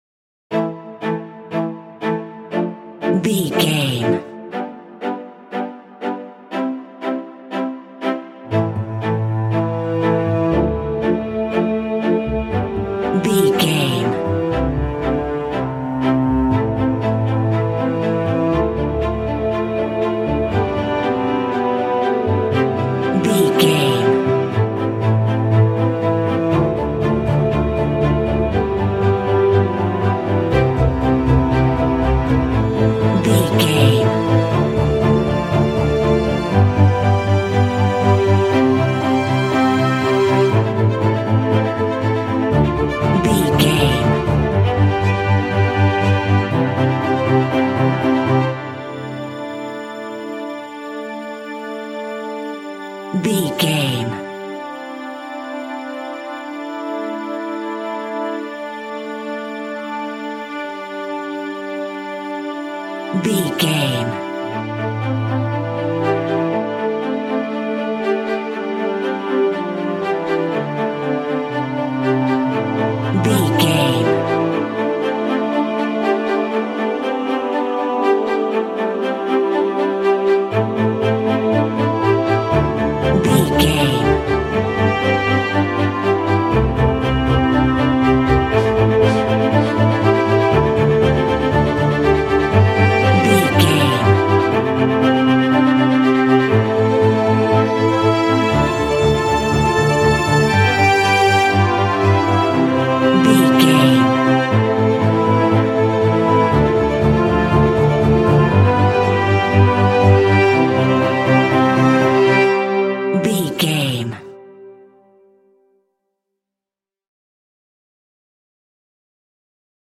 Epic / Action
Fast paced
Ionian/Major
dark
driving
repetitive
cinematic
film score
classical guitar